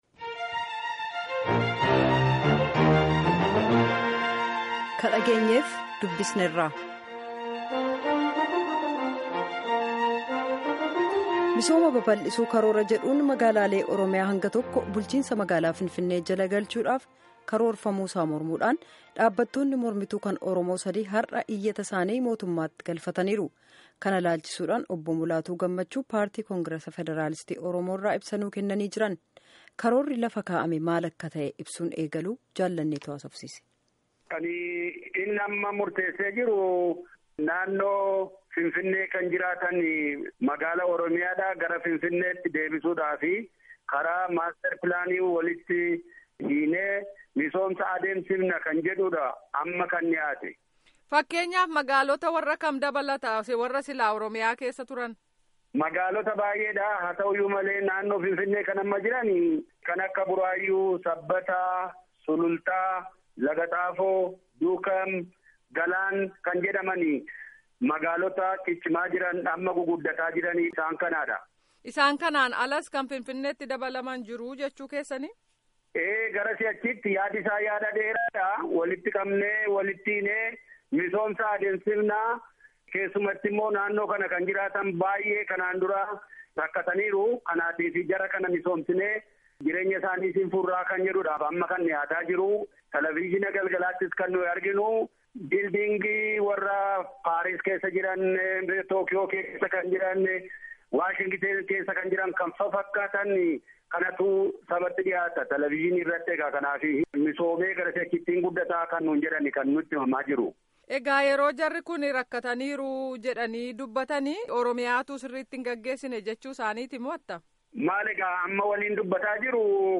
Guutummaa gaaffii fi deebii kanaa dhaggeeffadhaa